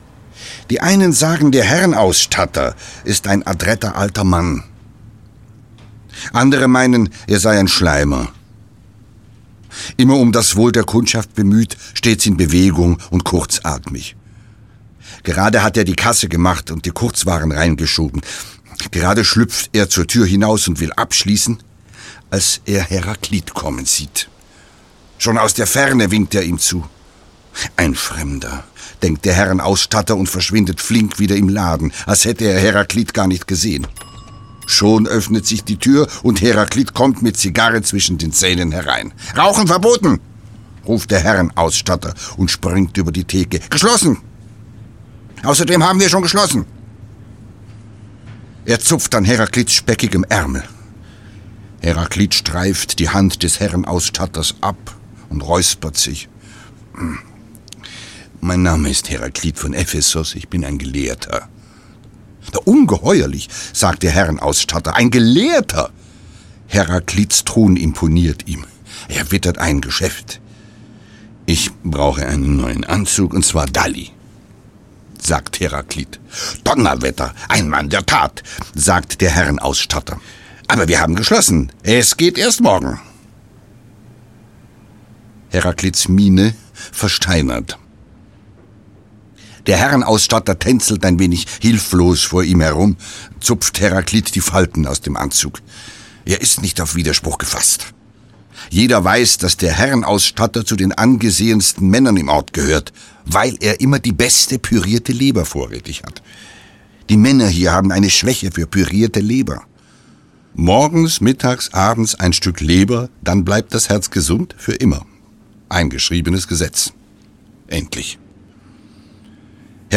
Ein schönes Leben - Martin Becker - Hörbuch